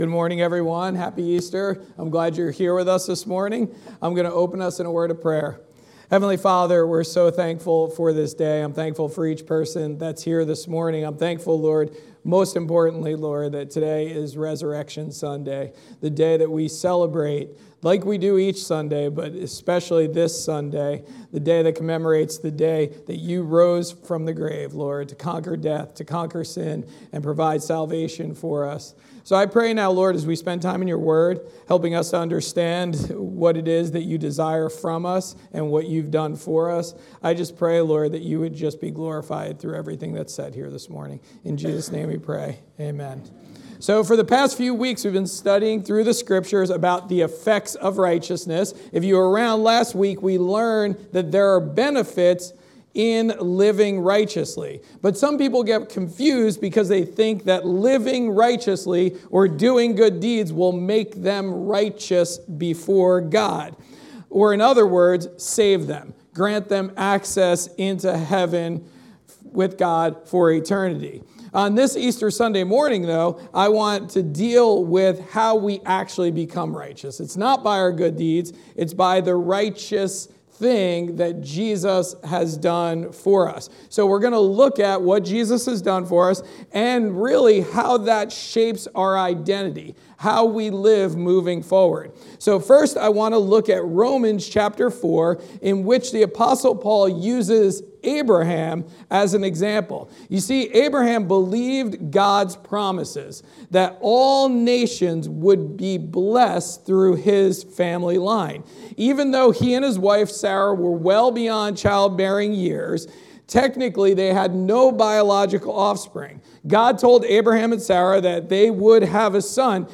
Sermons | Forked River Baptist Church